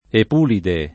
epulide [ ep 2 lide ]